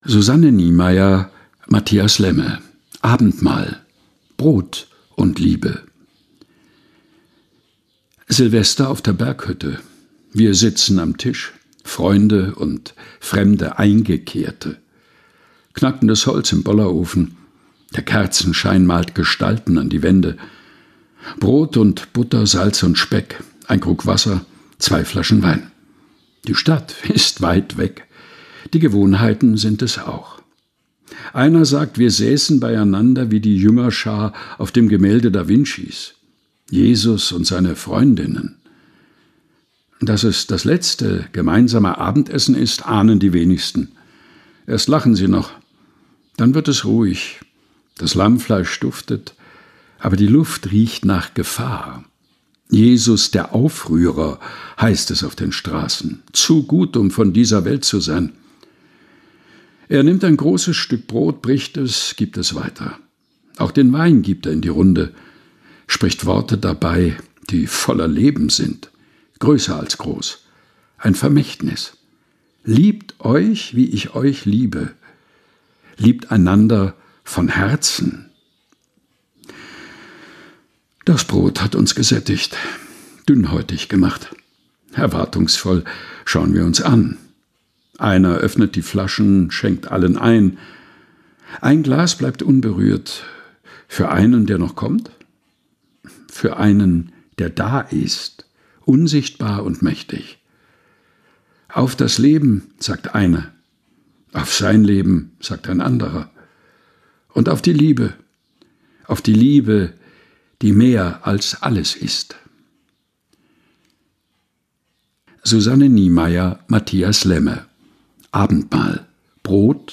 Texte zum Mutmachen und Nachdenken - vorgelesen von